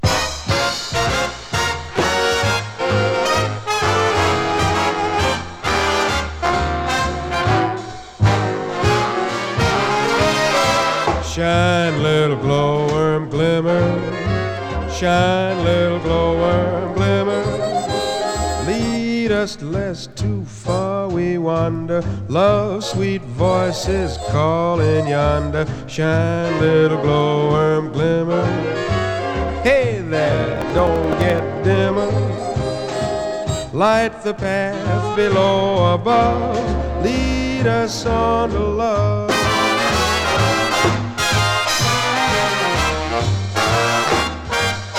Jazz, Pop, Vocal, Big Band　USA　12inchレコード　33rpm　Stereo